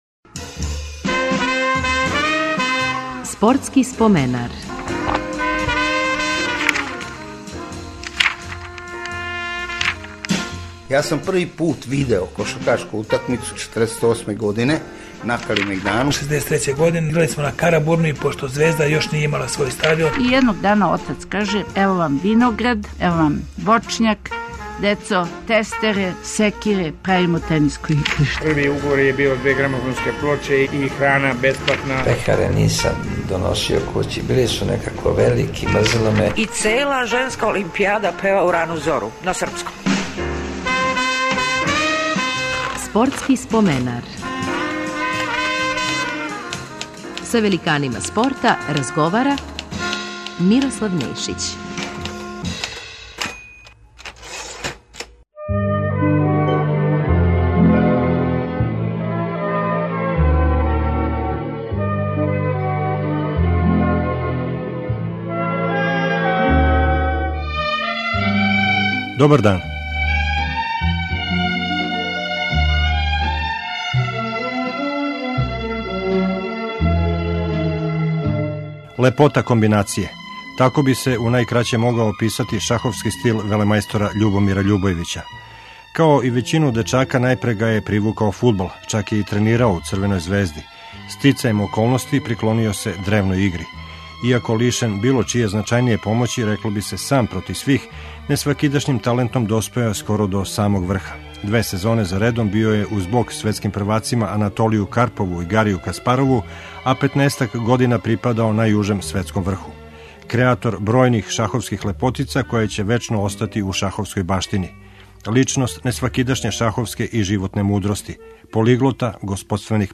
Репризираћемо разговор са Љубомиром Љубојевићем.